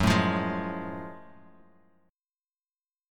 Listen to F#mM9 strummed